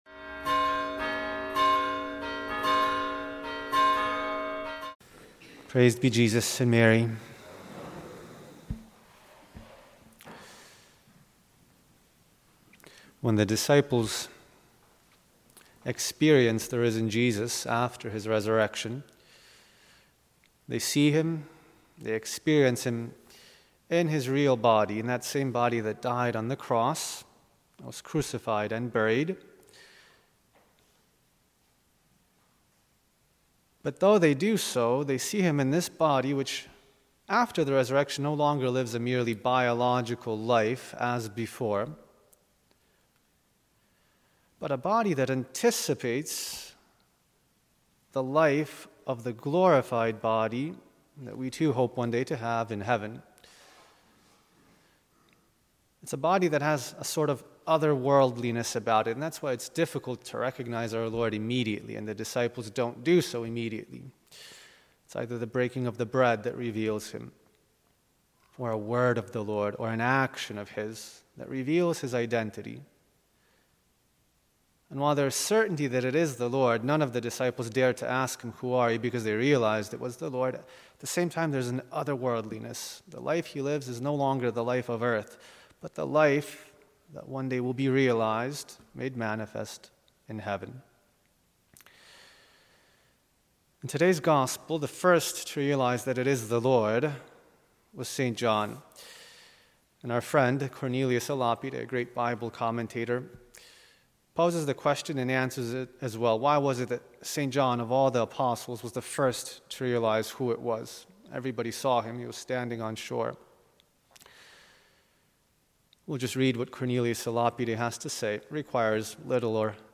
Homily